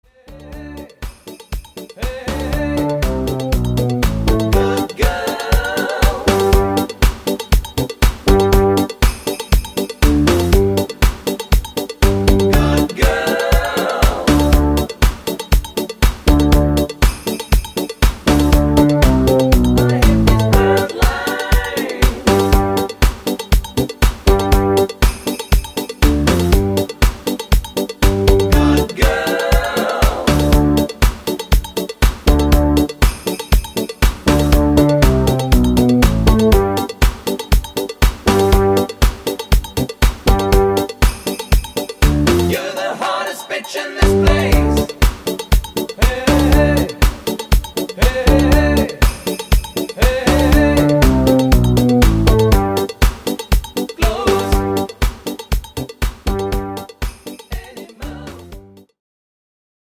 No Rap Version